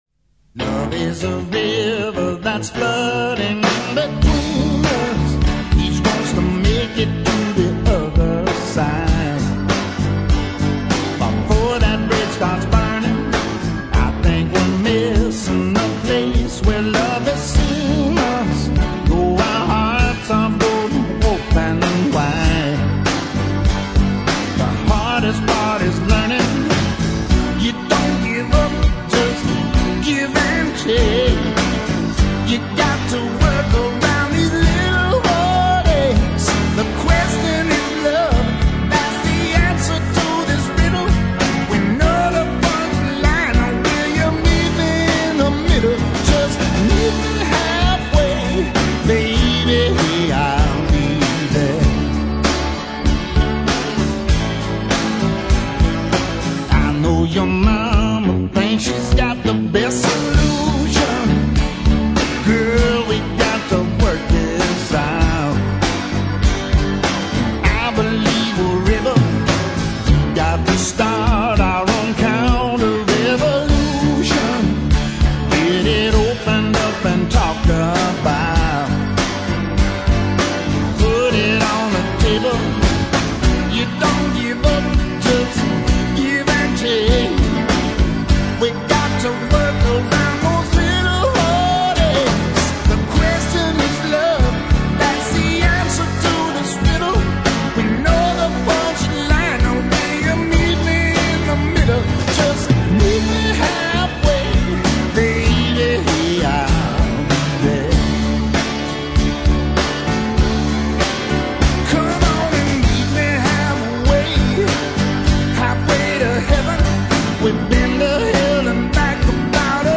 (a bit hissy)
16 Tracks of B-Sides and Outtakes